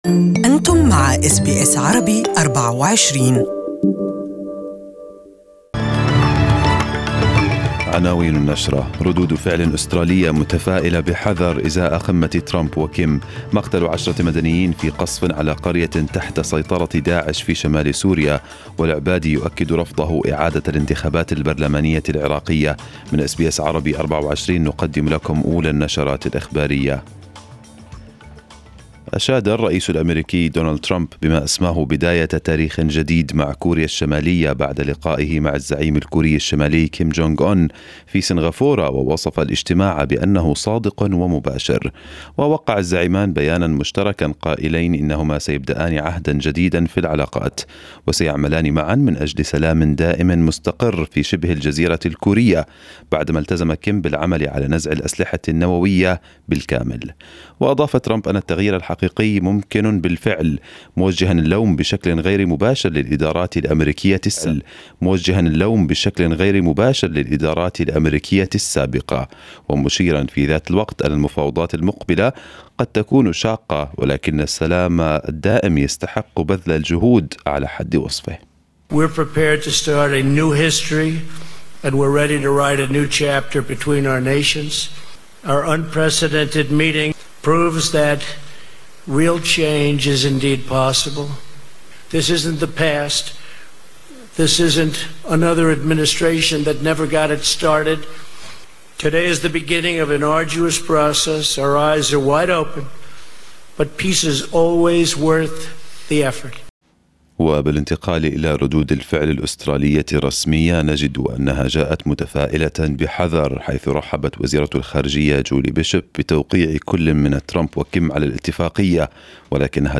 Arabic News Bulletin 13/06/2018